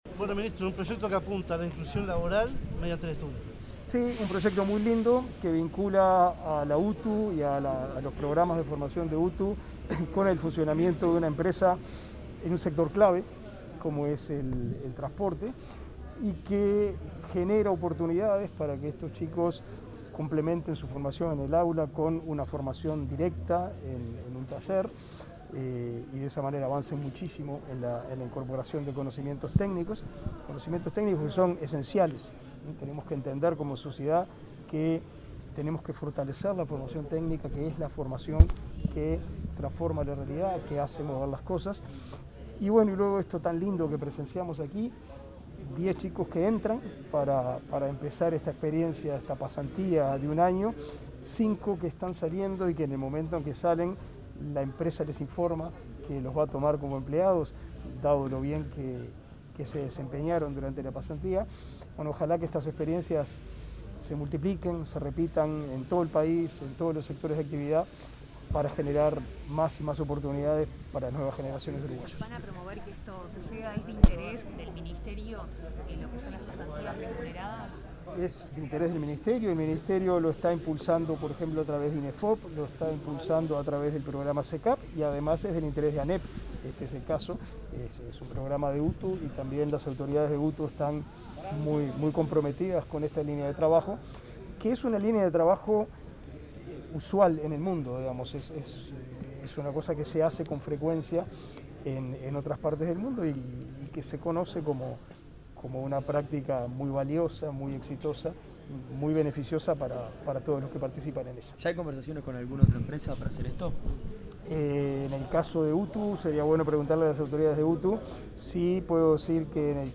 Declaraciones del ministro de Educación y Cultura, Pablo da Silveira